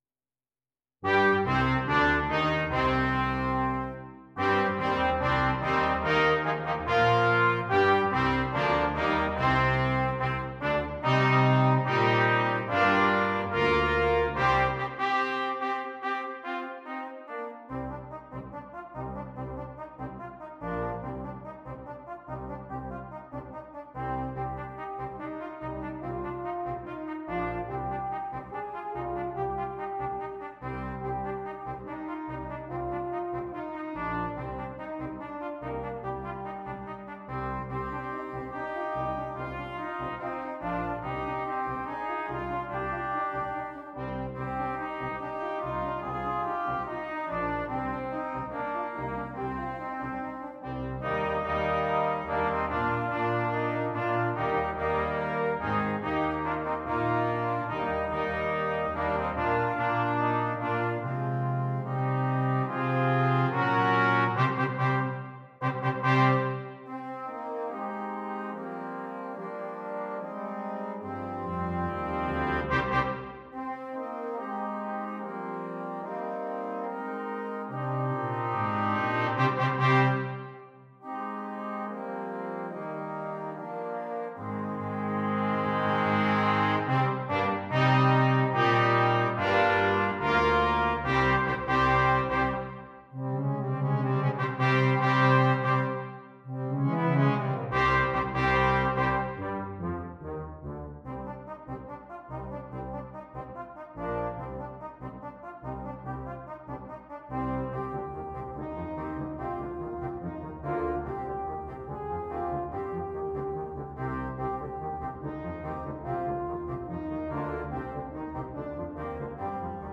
Brass Quintet
The music is always moving forward-advancing.